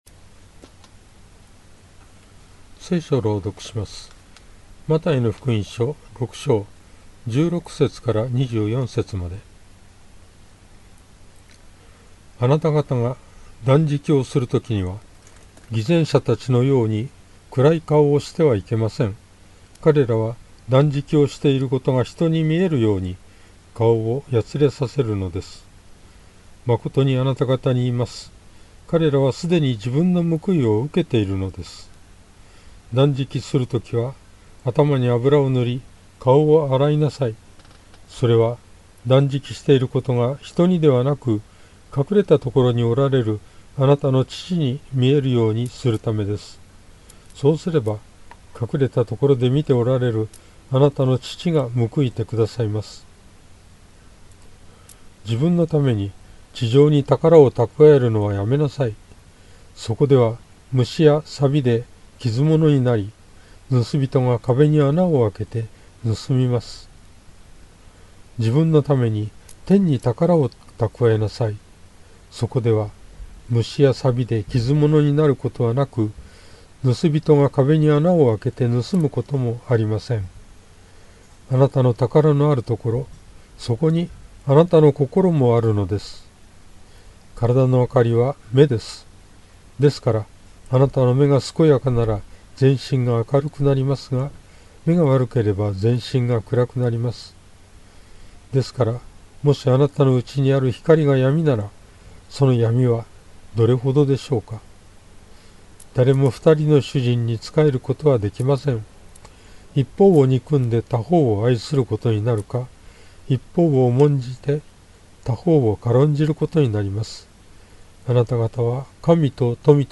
BibleReading_Math6.16-24.mp3